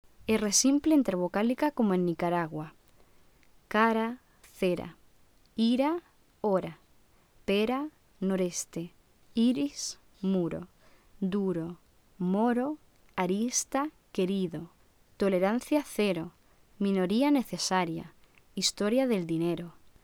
/ɾ/ vibrante simple alveolar sonora
[ɾ] simple, como en Nicaragua (intervocálica)